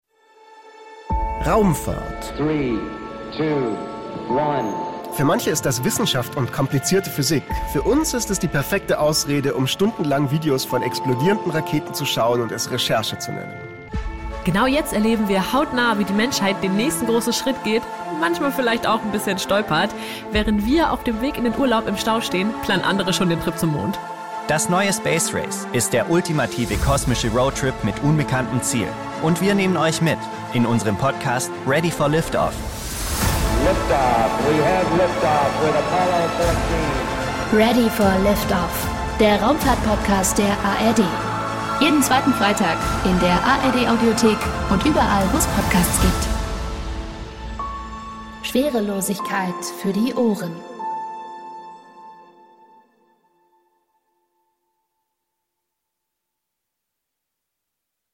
Im Podcast „Ready for Liftoff“ dreht sich alles um diese besonderen Momente der Raumfahrtgeschichte, aber auch um aktuelle Missionen, spannende Zukunftsvisionen und überraschende Einblicke in die Welt der Astronauten und Forscher. Locker erzählt, unterhaltsam und immer verständlich - ohne komplizierte Physik.